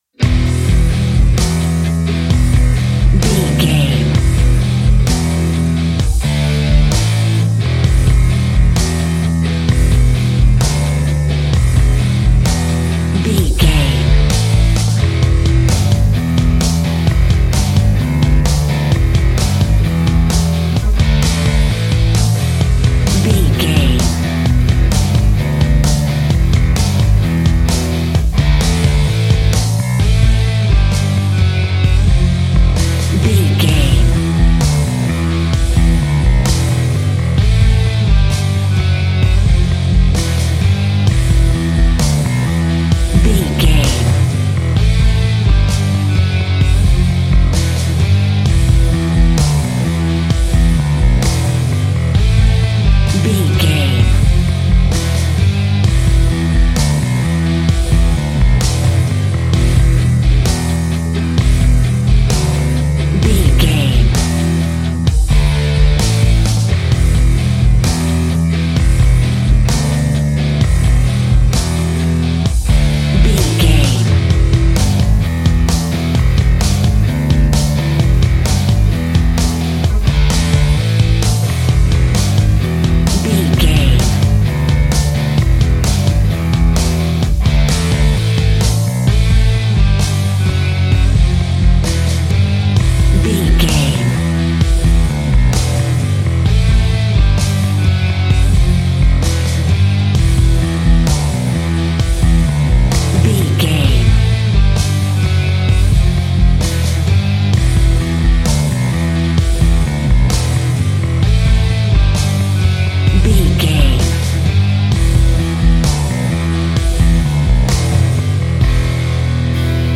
Epic / Action
Fast paced
Aeolian/Minor
hard rock
blues rock
distortion
instrumentals
Rock Bass
heavy drums
distorted guitars
hammond organ